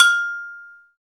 PRC XAGOGO07.wav